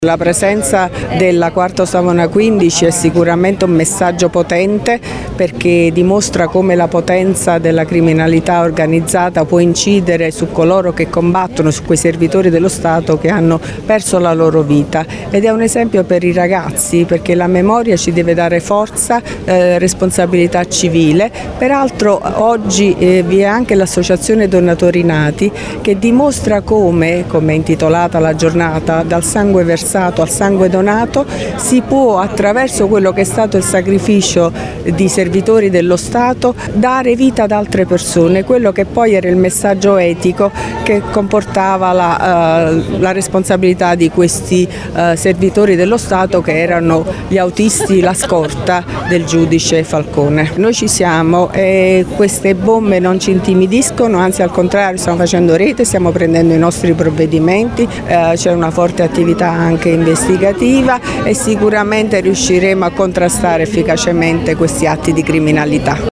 Si è tenuta questa mattina in Piazza del Popolo a Latina la cerimonia in occasione dello svelamento della teca della “Quarto Savona 15” l’auto della scorta del giudice Giovanni Falcone, distrutta nella strage di Capaci il 23 maggio 1992 in cui persero la vita oltre al magistrato e alla moglie Francesca Morvillo anche i tre agenti della scorta, Antonio Montinaro, Rocco Dicillo e Vito Schifani.
Il valore simbolico della memoria e l’importanza di parlare di legalità con i giovani dalle voci del Prefetto Vittoria Ciaramella e del Sindaco Matilde Celentano, anche in relazione al delicato momento storico in cui versa la città di Latina:
prefetto-qs15.mp3